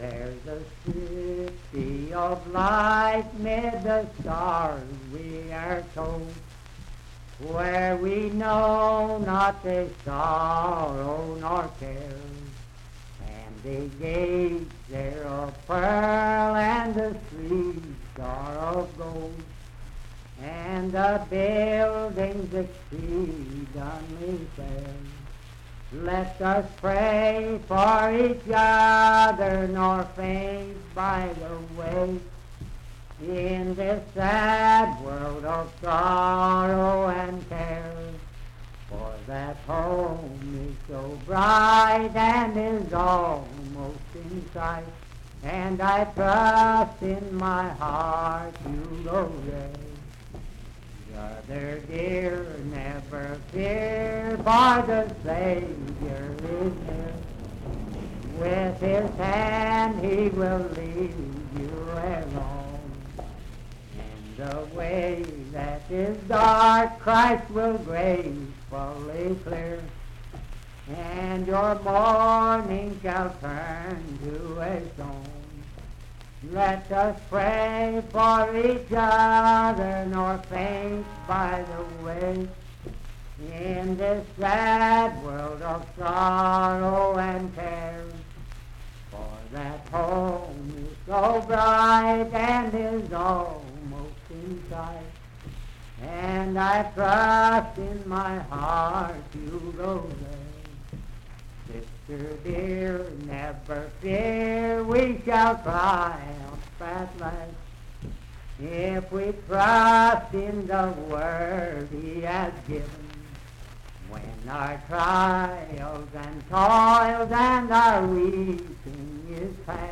Unaccompanied vocal music and folktales performed
Hymns and Spiritual Music
Voice (sung)
Parkersburg (W. Va.), Wood County (W. Va.)